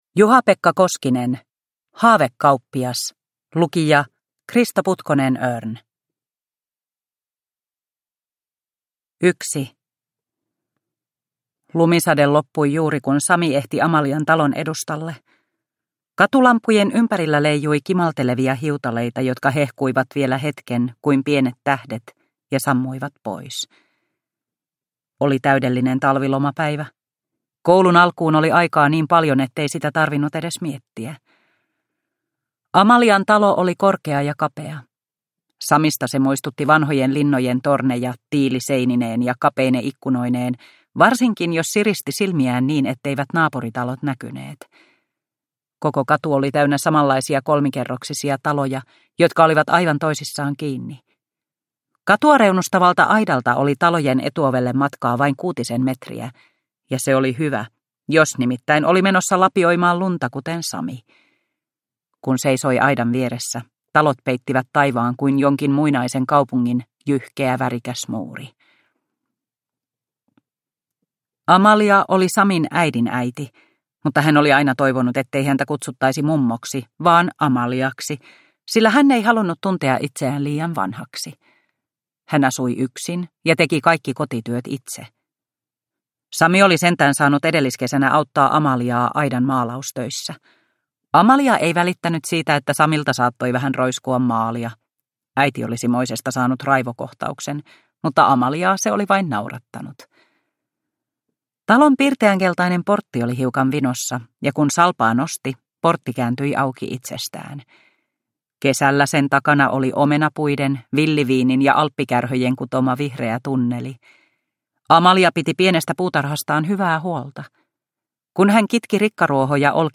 Haavekauppias – Haavekaupunki 1 – Ljudbok